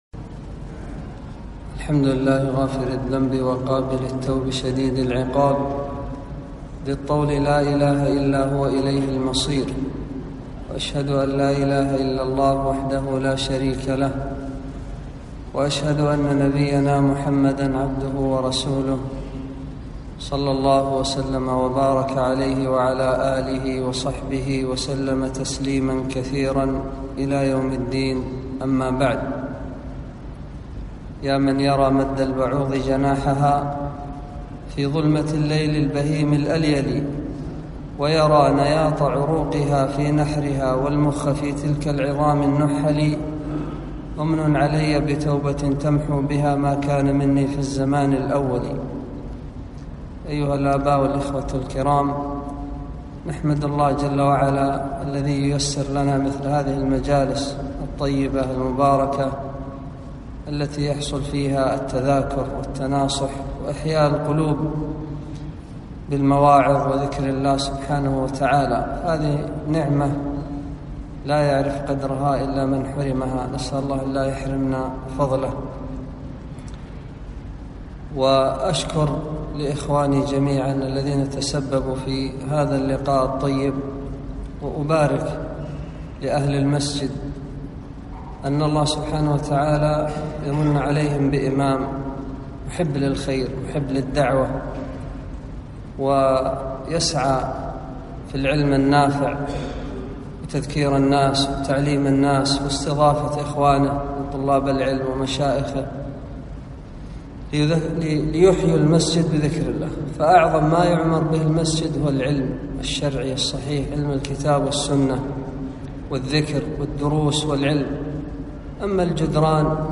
محاضرة - عواقب الذنوب